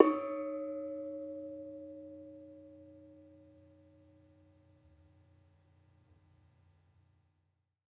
Gamelan Sound Bank
Bonang-D#3-f.wav